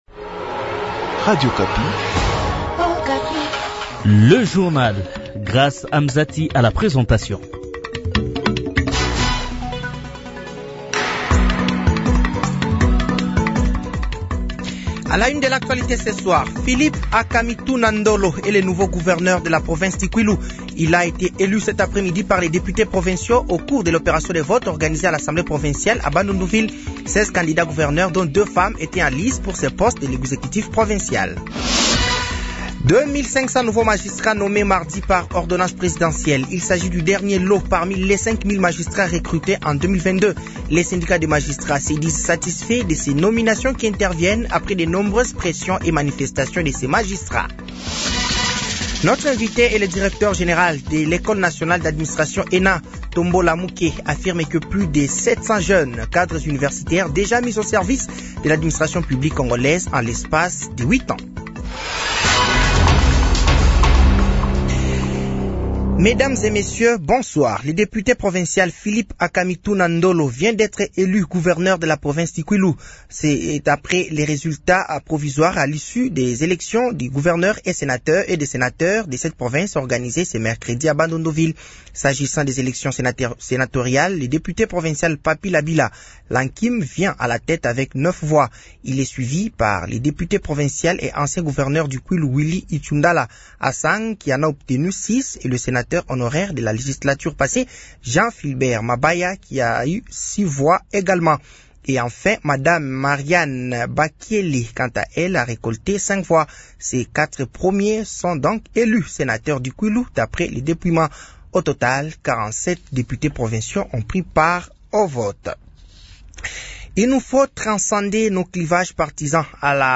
Journal français de 18h de ce mercredi 02 avril 2025